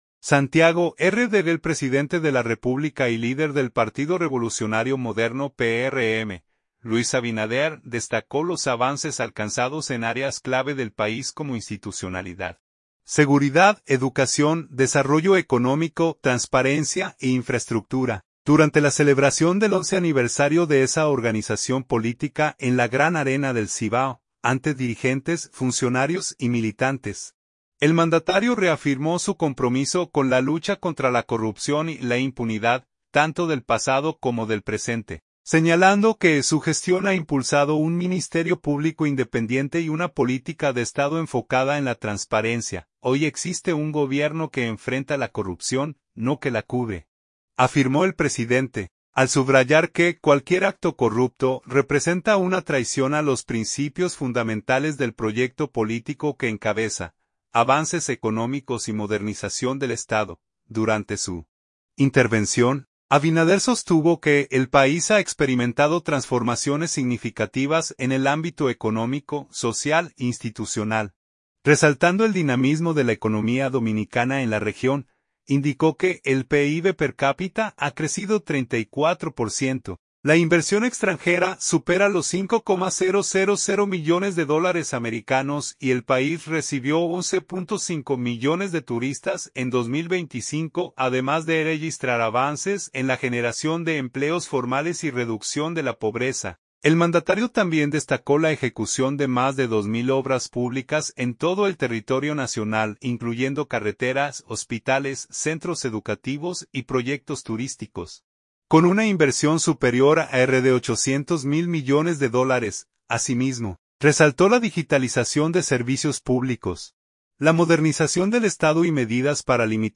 Santiago, R.D. — El presidente de la República y líder del Partido Revolucionario Moderno (PRM), Luis Abinader, destacó los avances alcanzados en áreas clave del país como institucionalidad, seguridad, educación, desarrollo económico, transparencia e infraestructura, durante la celebración del 11 aniversario de esa organización política en la Gran Arena del Cibao.